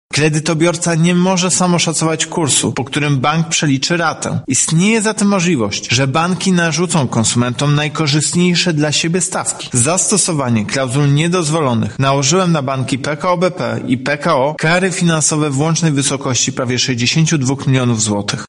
-mówi prezes urzędu, Tomasz Chróstny.